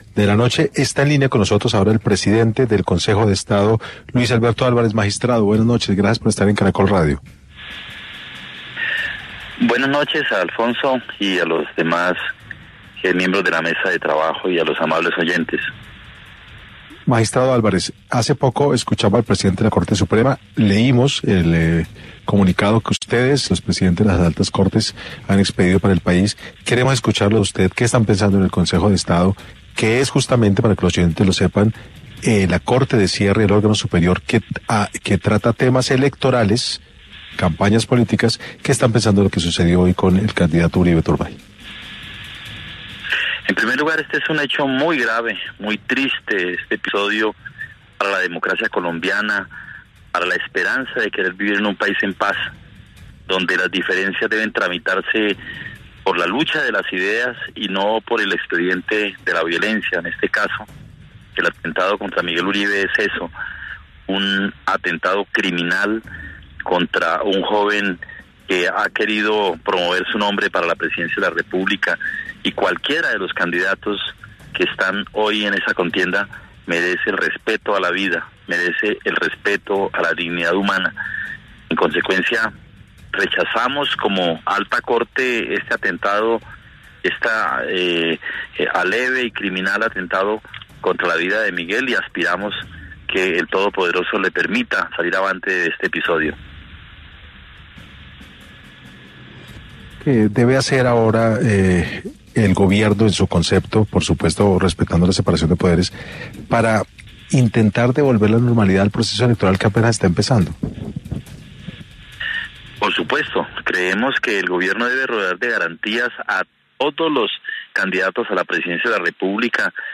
En diálogo con Caracol Radio, Luis Alberto Álvarez, rechazó el atentado contra Miguel Uribe Turbay.